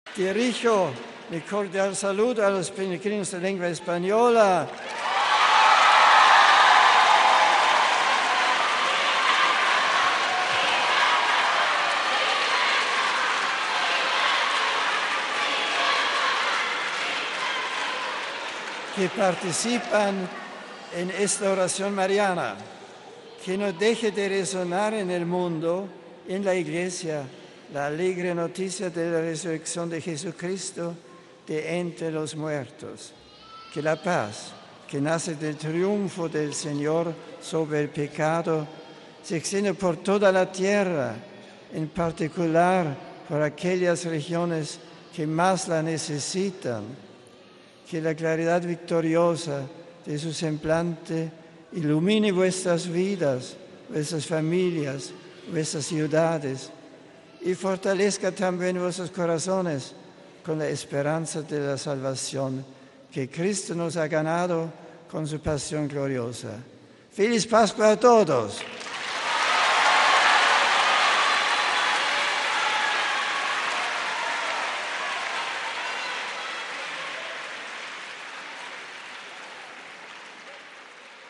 Después del rezo del Regina Coeli el Papa ha saludado en varias lenguas este ha sido el saludo en español: